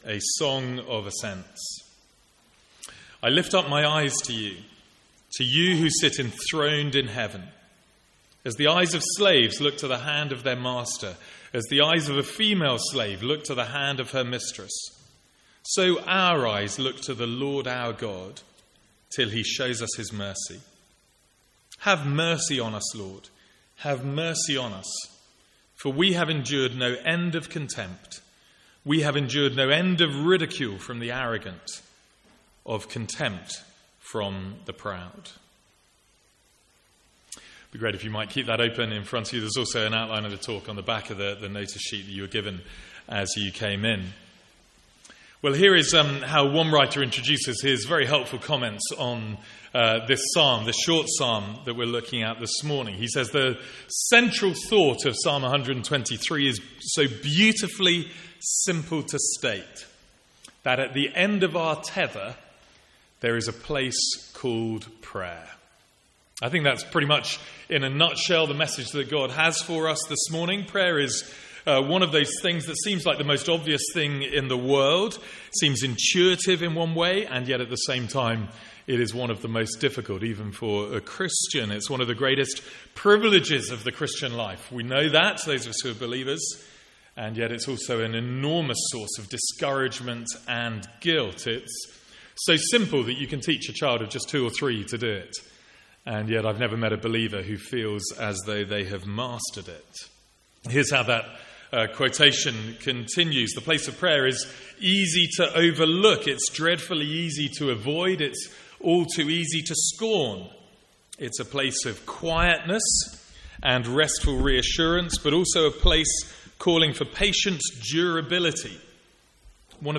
Sermons | St Andrews Free Church
From the Sunday morning series in the Psalms.